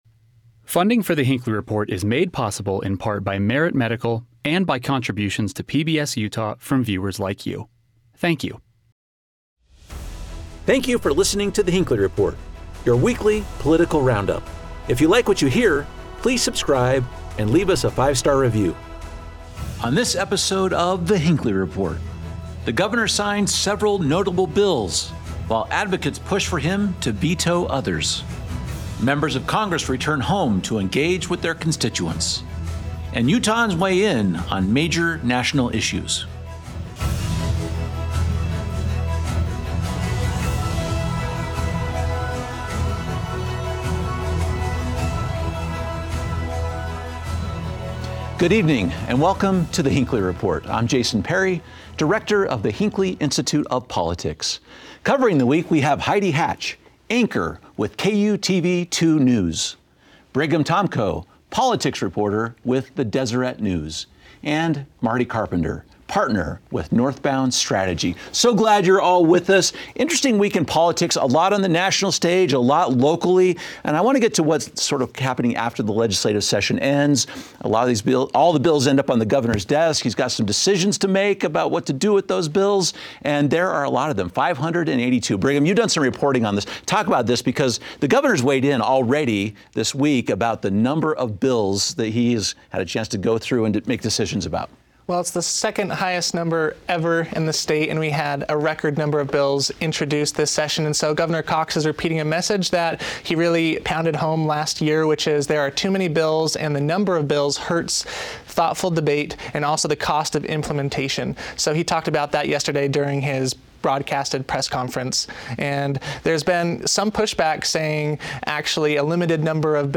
Our expert panel discusses why the governor may, or may not, use his constitutional authority to override the legislature.